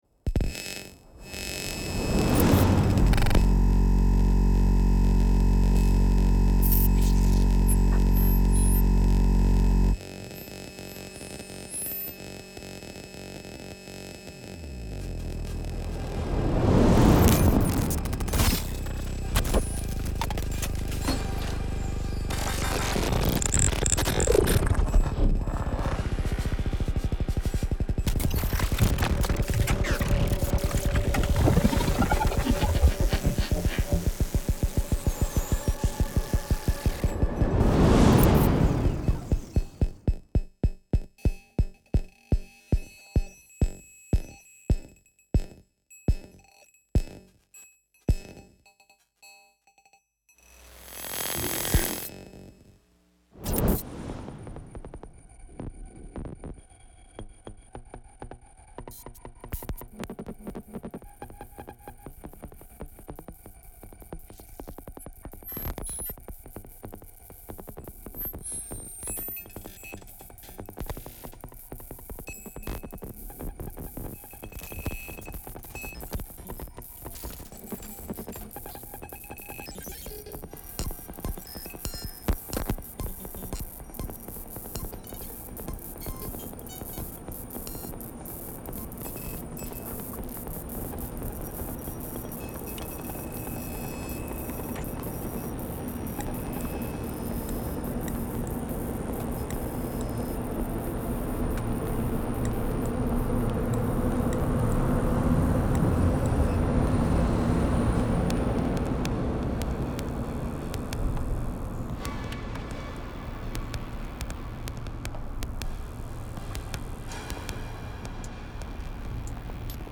Installation sonore présentée en 2017
Réduction stéréo de la pièce pour 16 haut-parleurs